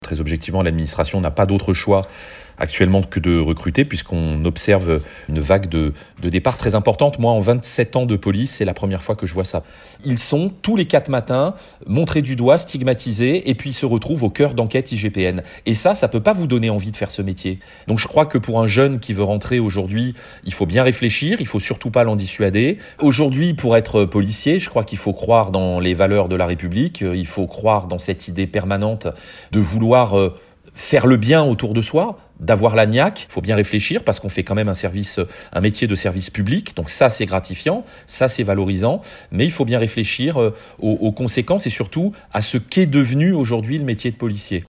son-journal-policiers-6502.mp3